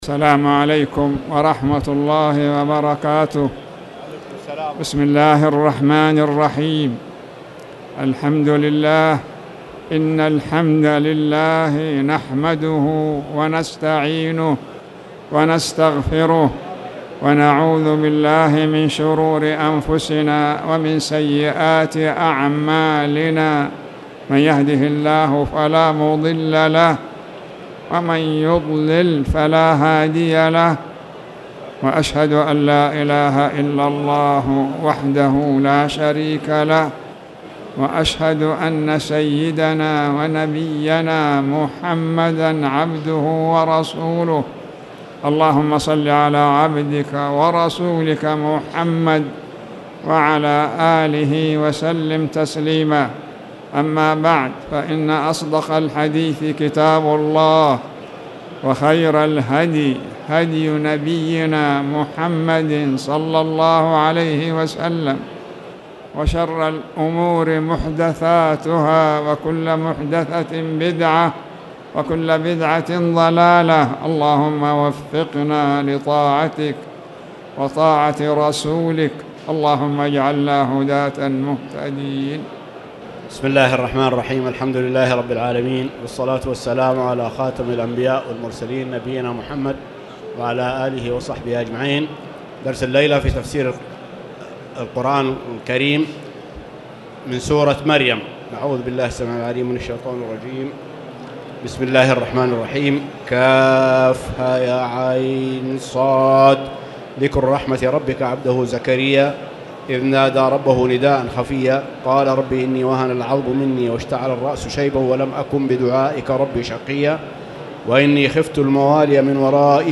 تاريخ النشر ٦ ربيع الأول ١٤٣٨ هـ المكان: المسجد الحرام الشيخ